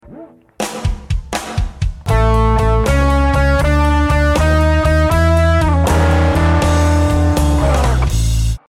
Sticking with the key of A for our example the V chord E7 can be preceded by either a F7 or an Eb7.
Blues Turnaround Lick 6